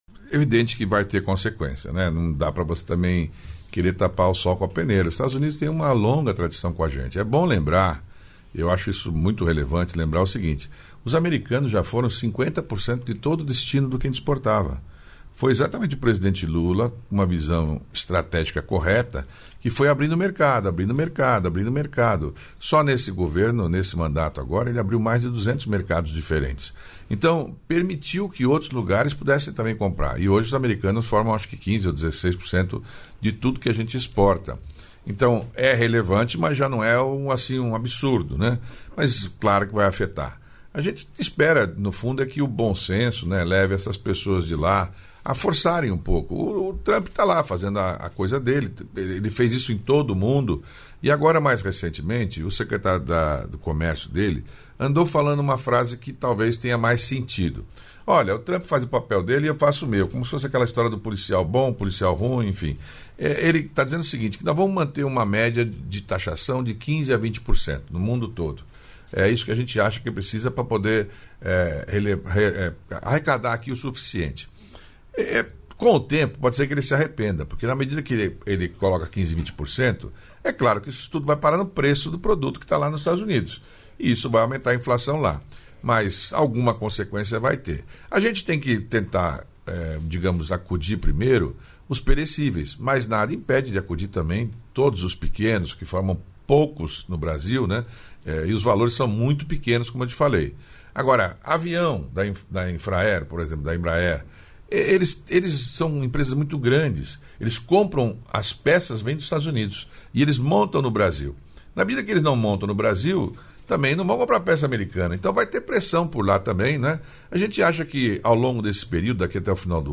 Trecho da participação do ministro do Empreendedorismo, da Microempresa e da Empresa de Pequeno Porte, Márcio França, no programa "Bom Dia, Ministro" desta quarta-feira (30), nos estúdios da EBC em Brasília (DF).